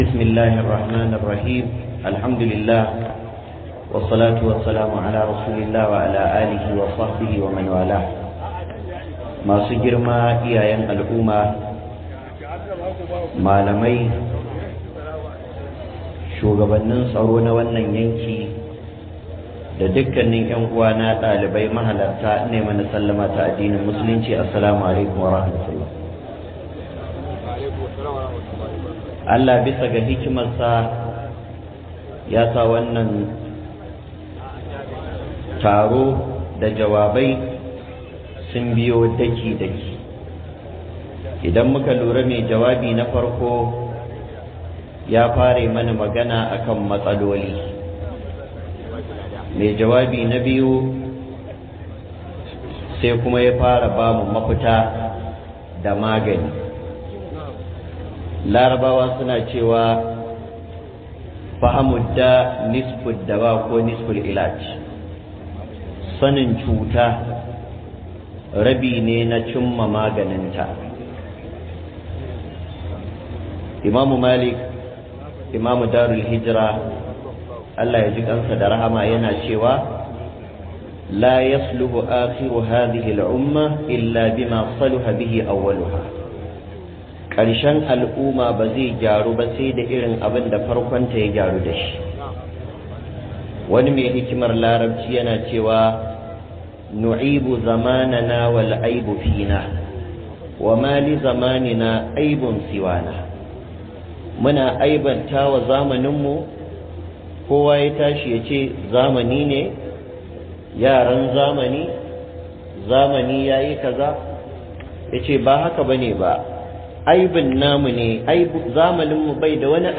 ILLAR SHAYE-SHAYE DA DABA DA MUHIMMACIN ZAMAN LAFIYA - MUHADARA